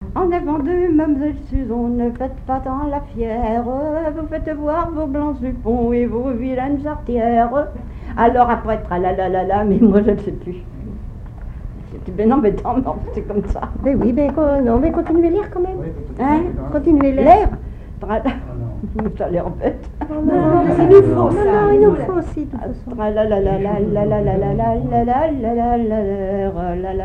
Chants brefs - A danser
danse : branle : avant-deux
Enquête Arexcpo en Vendée-Association Héritage-C.C. Herbiers
Pièce musicale inédite